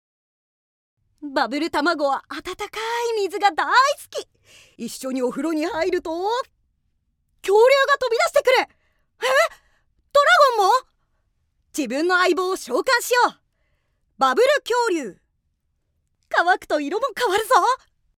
◆おもちゃのCM◆